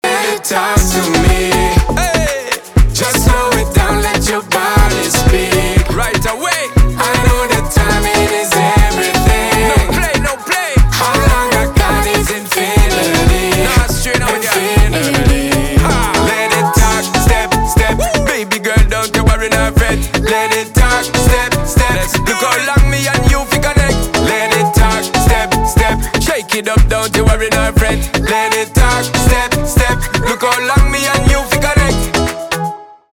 поп
танцевальные